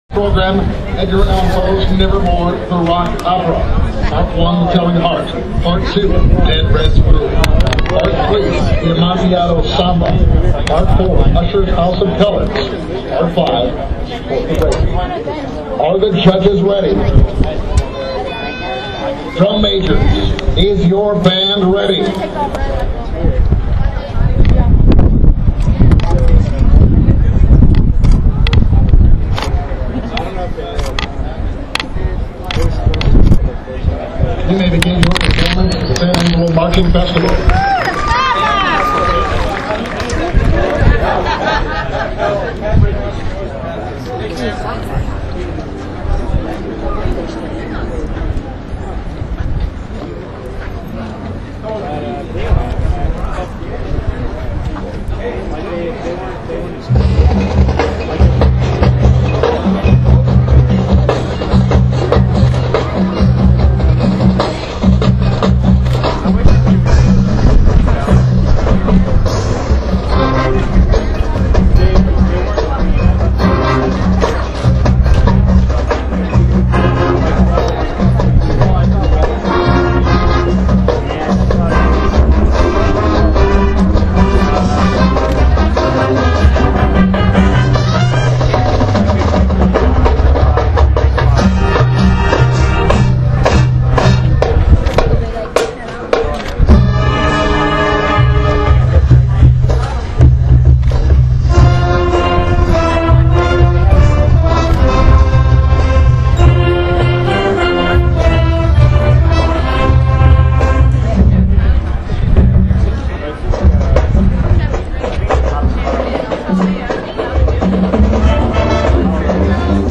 Claxton Photography | San Angelo Marching Contest, 10/2/2010
Congratulations band on receiving a Division 1 rating and outstanding color guard and woodwind.